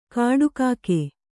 ♪ kāḍu kāke